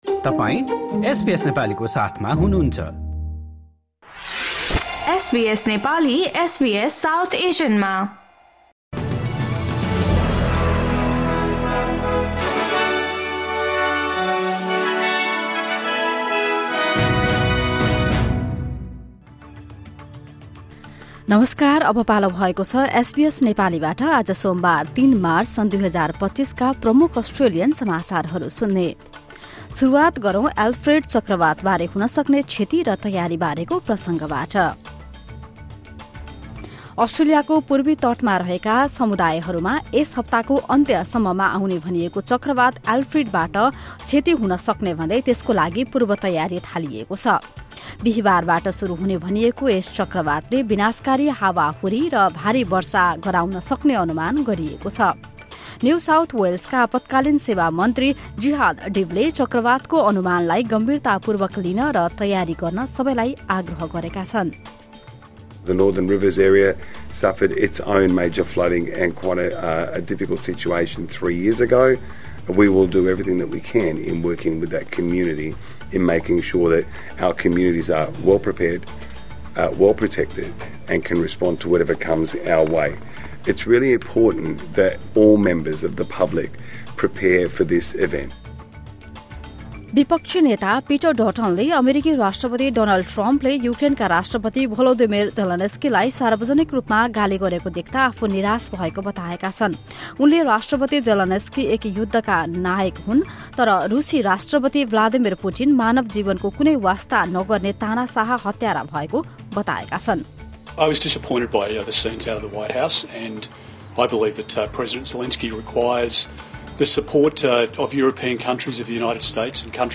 SBS Nepali Australian News Headlines: Monday, 3 March 2025